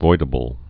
(voidə-bəl)